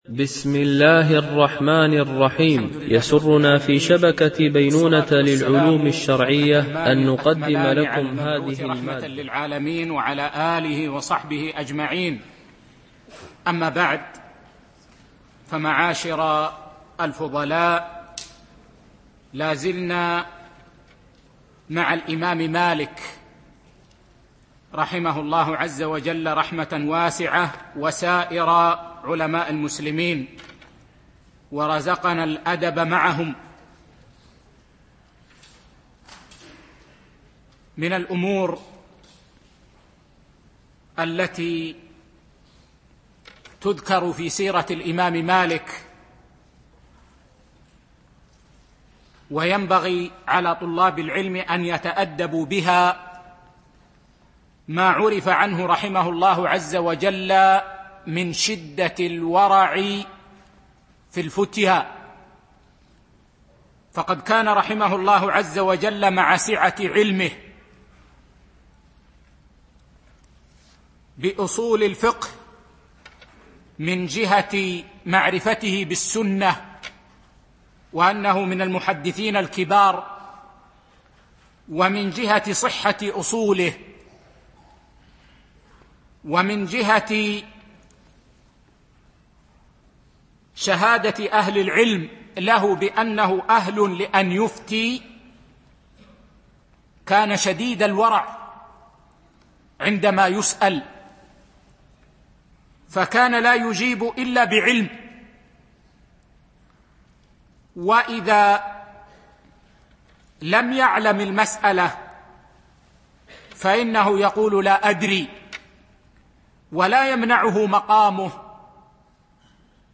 الإعلام بالأئمة الأربعة الأعلام - الدرس 3
دورة علمية